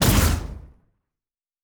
Sci Fi Explosion 19.wav